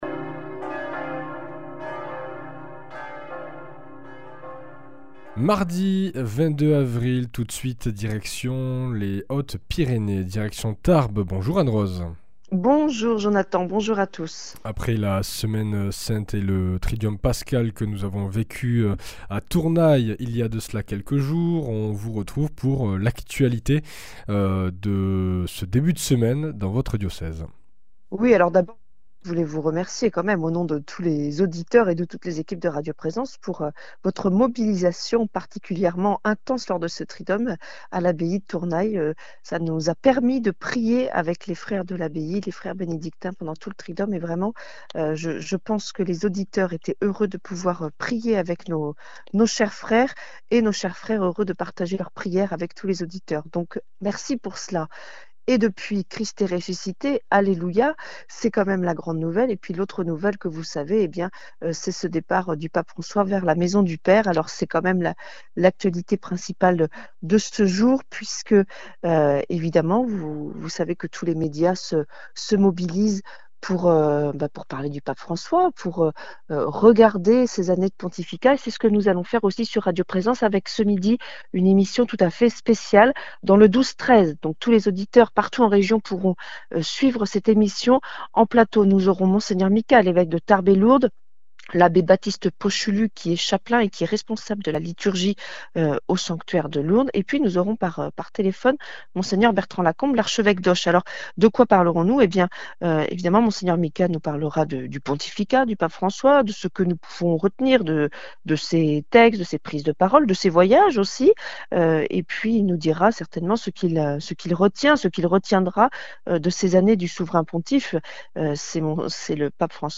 Le lundi de Pâques, le Pape François a été rappelé à Dieu. Mgr Jean-Marc Micas, évêque de Tarbes et Lourdes, partage avec nous son émotion et son espérance.